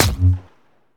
hit3.wav